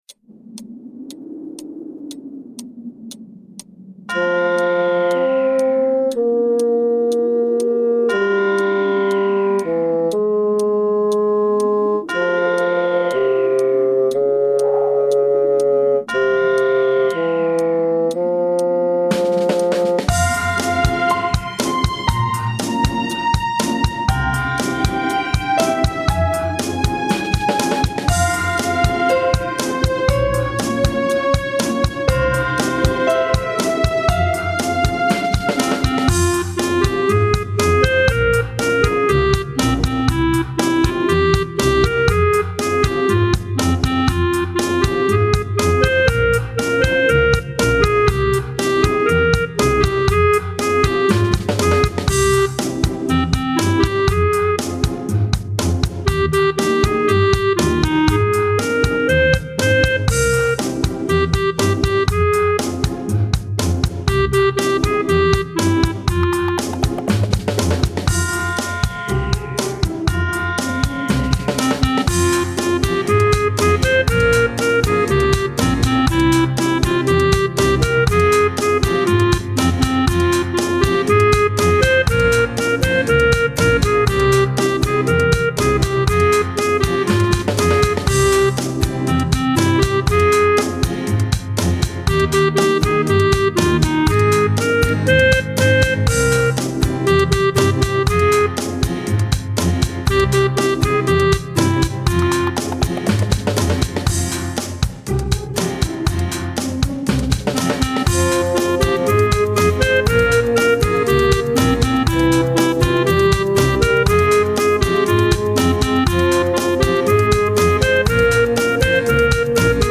• Качество: Хорошее
• Категория: Детские песни
караоке